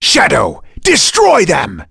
Dimael-Vox_Skill3.wav